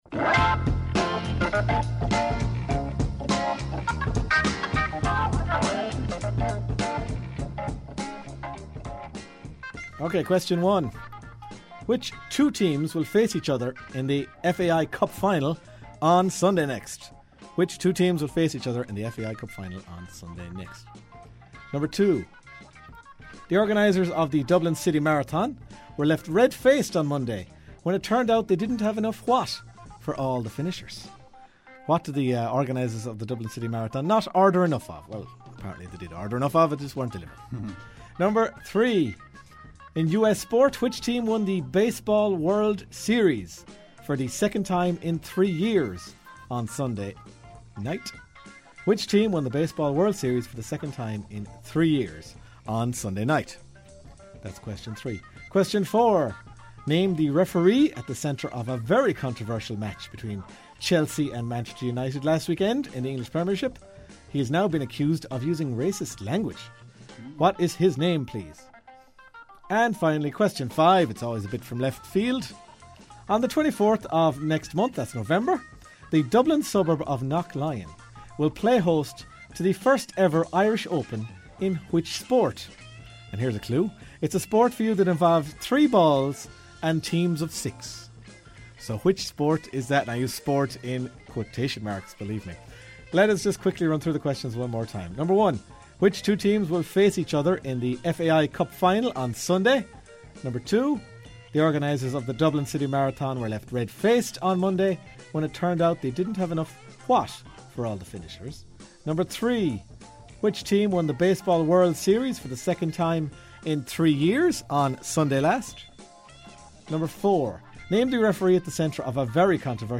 Five sports trivia questions from the Half-time Team Talk show on Claremorris Community Radio.